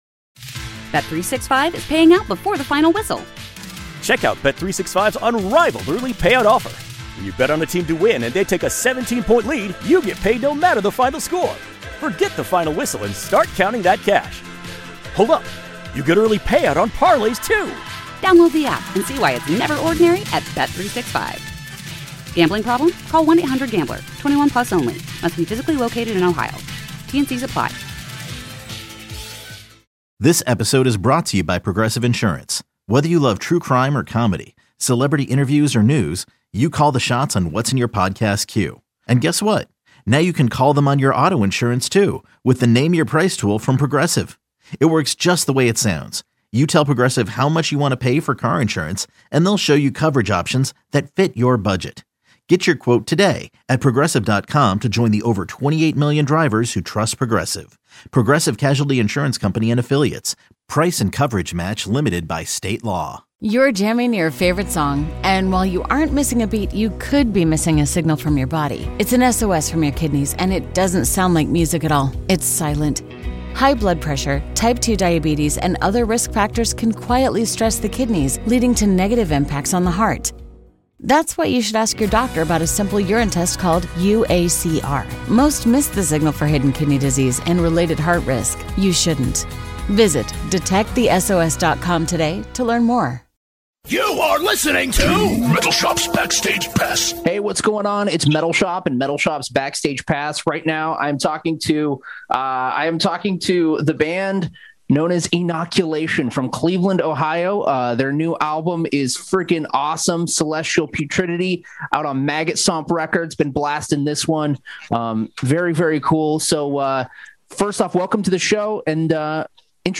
Fresh off the release of their killer new album "Celestial Putridity," I spoke with two thirds of the badass Cleveland death metal band Inoculation. Check out what they have to say about their celestial, mind bending brand of heaviness.